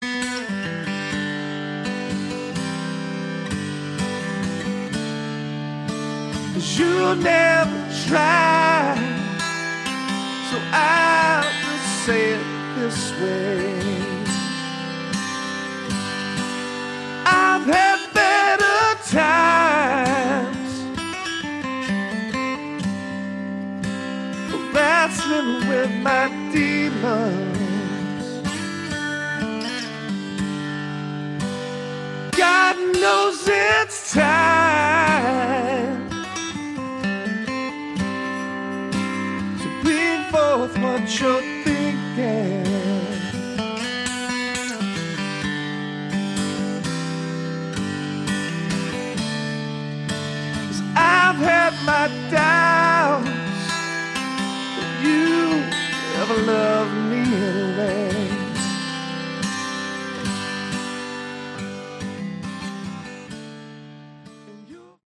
Category: Southern Rock
vocals
guitars
drums
bass
hammond, piano